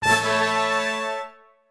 congrats.mp3